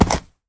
sounds / mob / horse / jump.mp3
jump.mp3